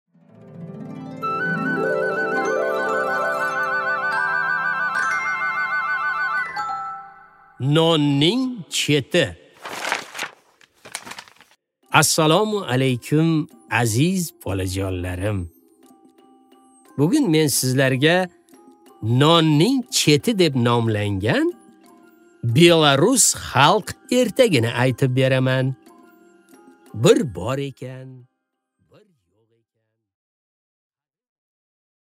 Аудиокнига Nonning chеti